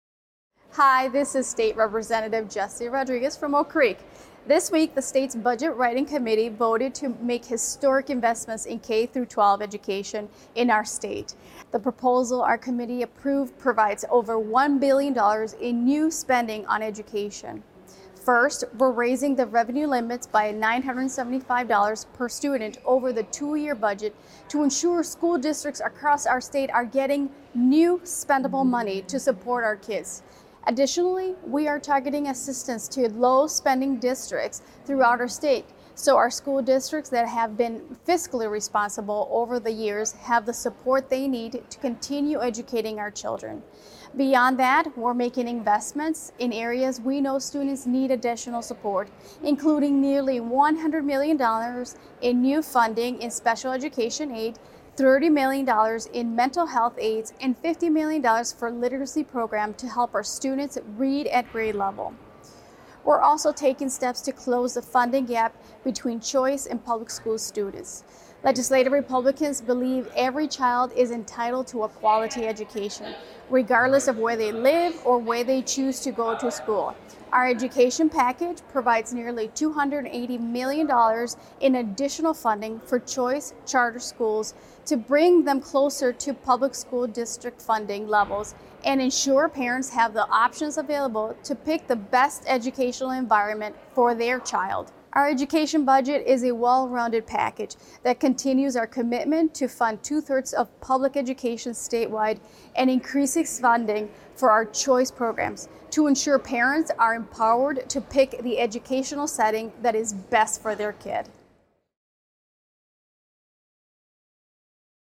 Weekly GOP radio address: Rep. Rodriguez on historic education investments - WisPolitics
MADISON – Representative Rodriguez (R – Oak Creek) released the weekly radio address on behalf of Wisconsin Legislative Republicans.